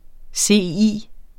Udtale [ ˈseˀˌiˀ ]